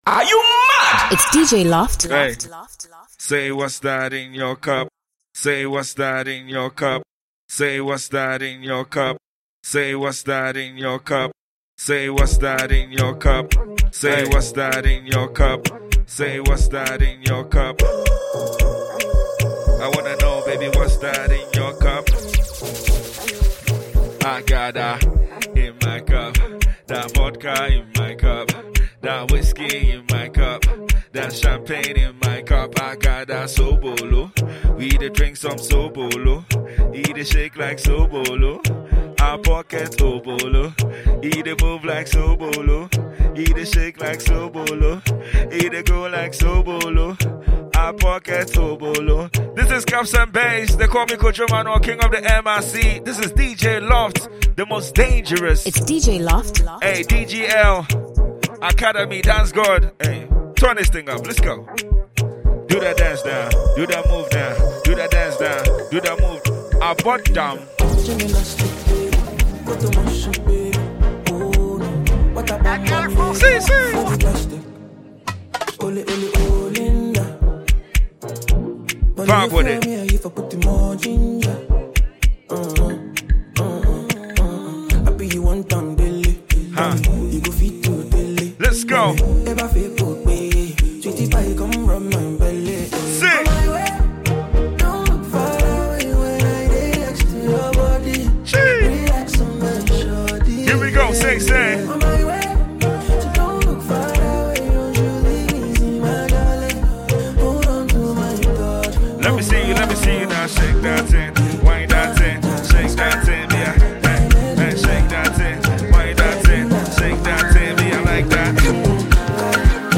” a trusted Ghana music mix for party lovers.
mix of the newest Afrobeats, amapiano, and party anthems